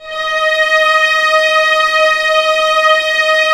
Index of /90_sSampleCDs/Roland LCDP13 String Sections/STR_Violins IV/STR_Vls7 f slo